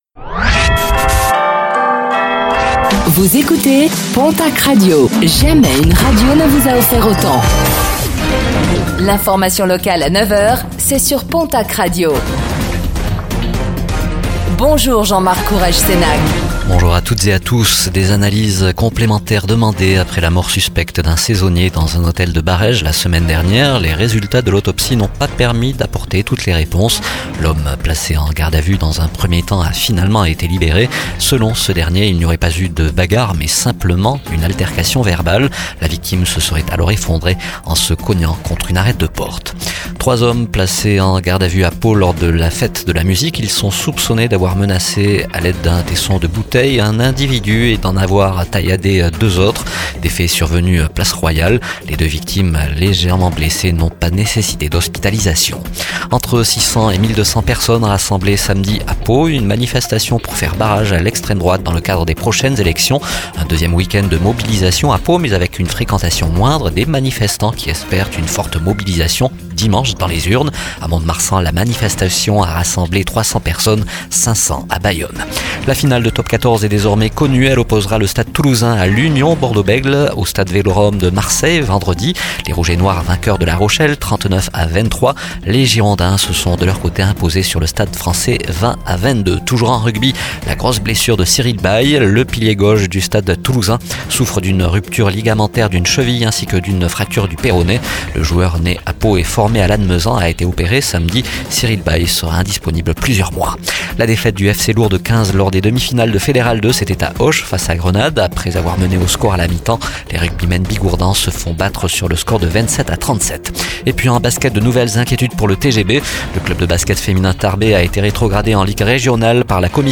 Infos | Lundi 24 juin 2024